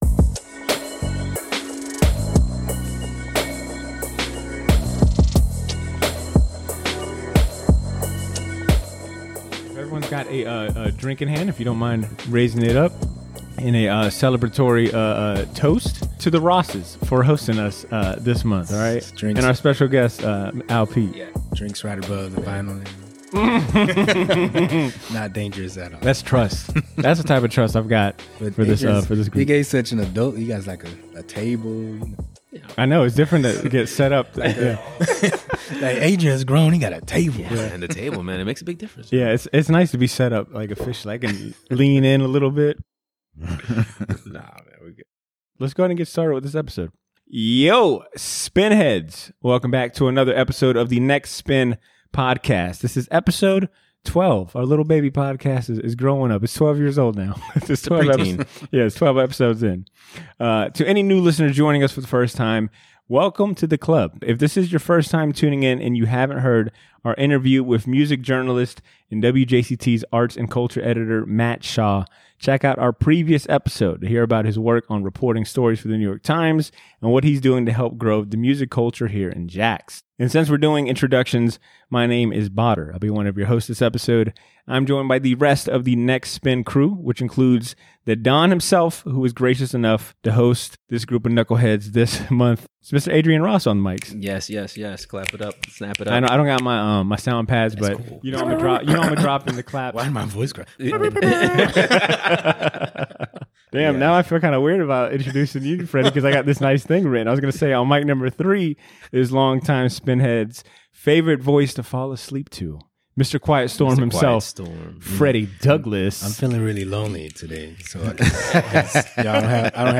joined us for this month's vinyl meet-up centered on Soul Music! The merits of the genre (00:13:07), it's close ties to hip-hop, and talk about the records they brought for the theme (00:30:38)